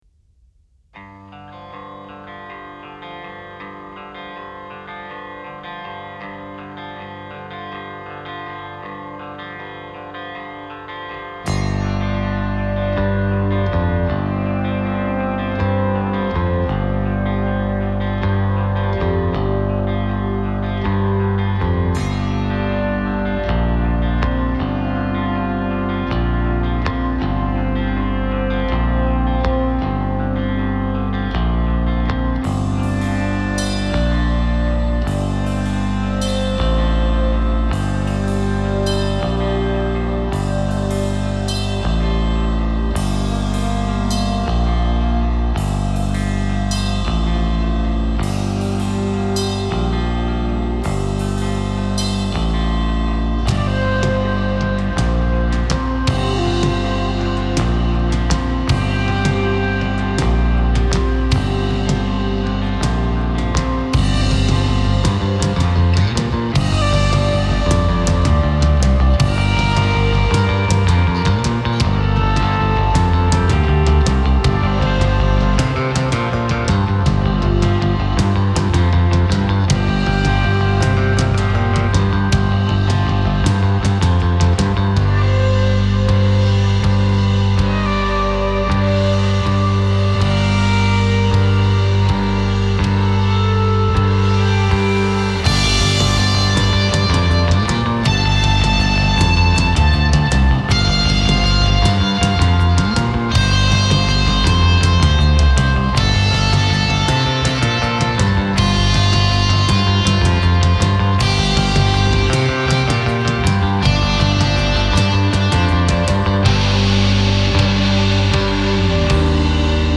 A heavy type of rock, veering between prog and post rock